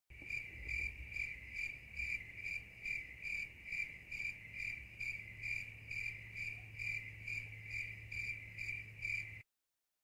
crickets.ogg